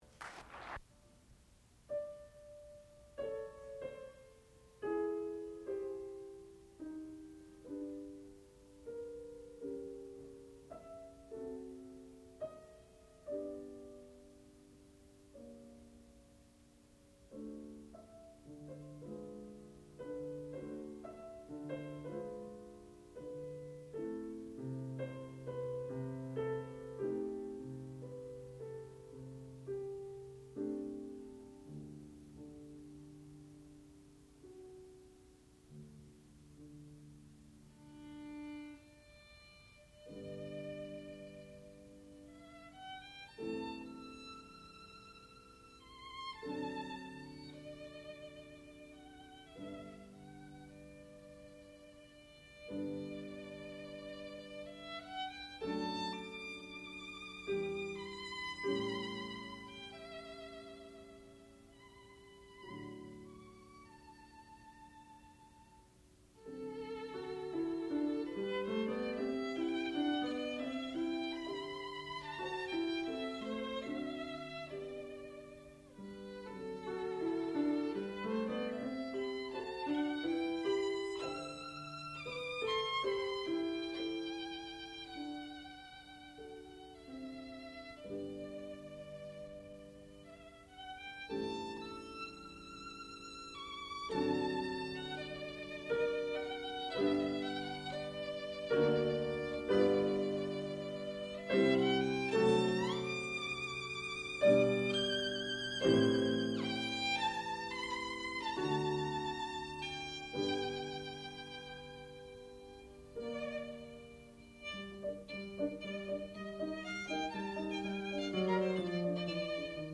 sound recording-musical
classical music
Eva Knardahl, piano (performer).